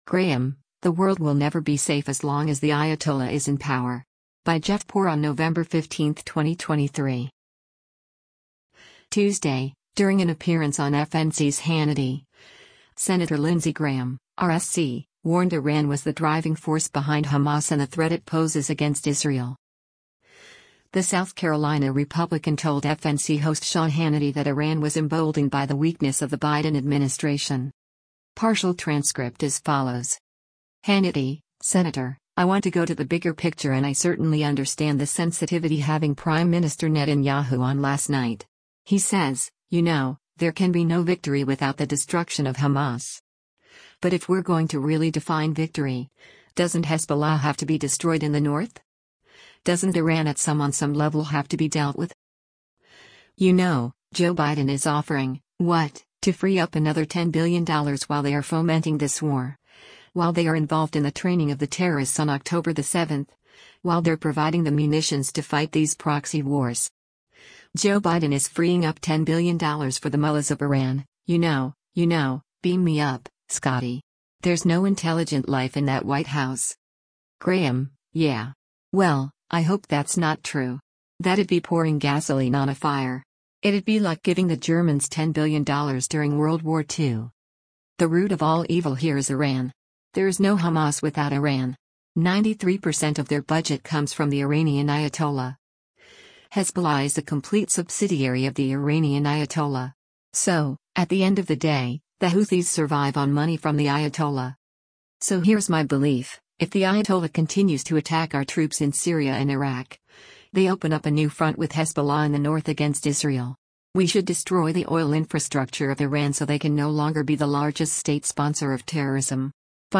Tuesday, during an appearance on FNC’s “Hannity,” Sen. Lindsey Graham (R-SC) warned Iran was the driving force behind Hamas and the threat it poses against Israel.
The South Carolina Republican told FNC host Sean Hannity that Iran was “emboldened” by the weakness of the Biden administration.